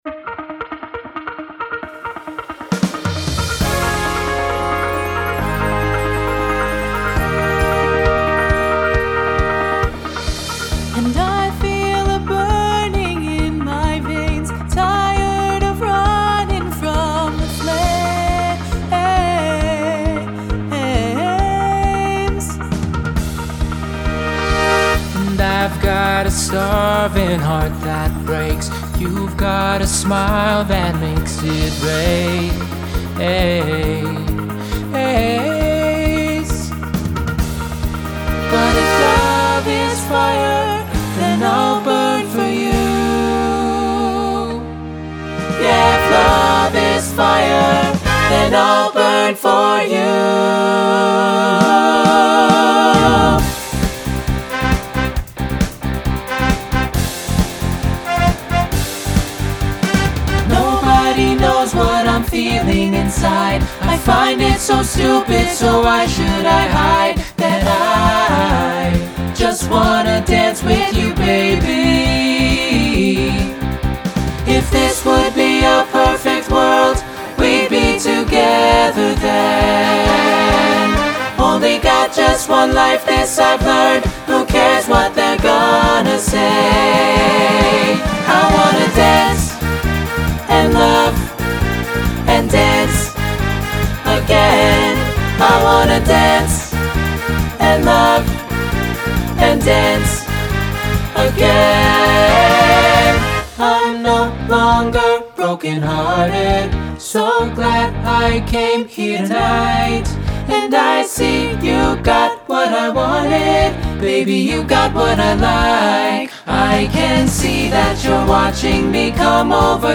Pop/Dance Instrumental combo
Voicing SATB